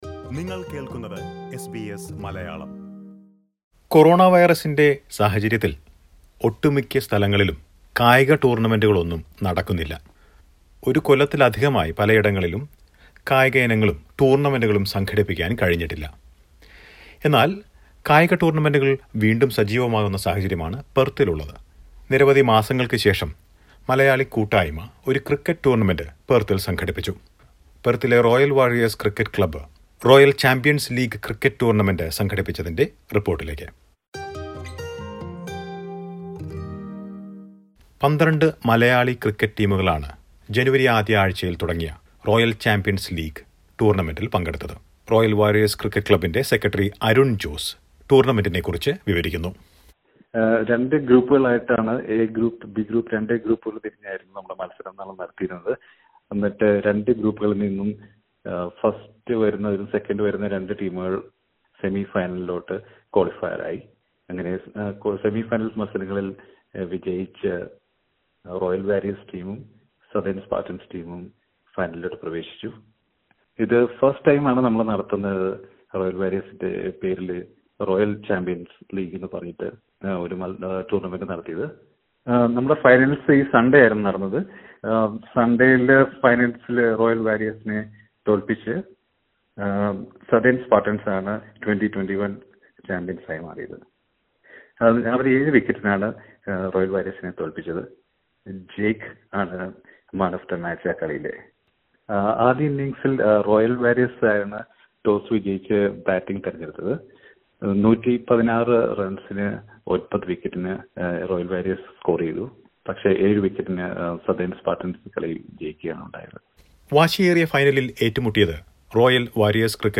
Cricket is back in Perth. Listen to a report on the recently concluded Royal Champions Cricket League tournament.